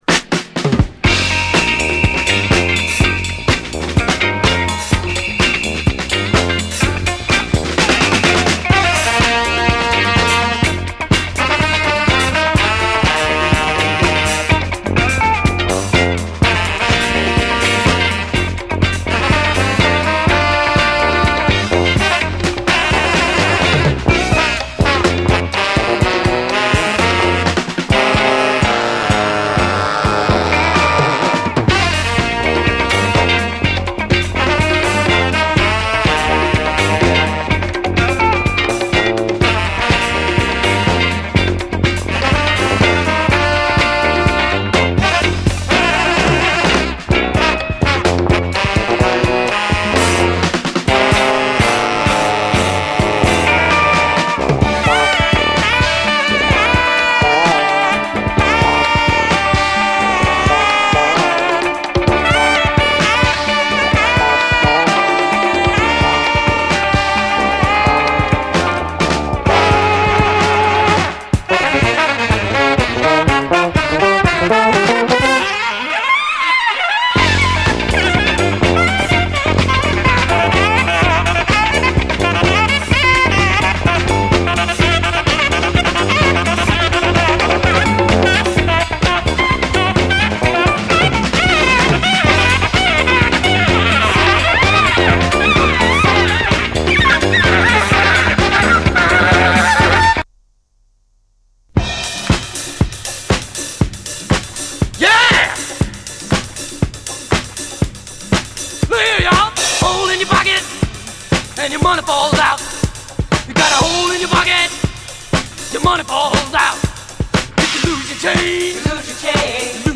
SOUL, JAZZ FUNK / SOUL JAZZ, 70's～ SOUL, JAZZ
A面でのファンキーなビート感が際立っており
しかしそれだけでなくメロウ・スピリチュアル・ジャズ・サイドもヤバい！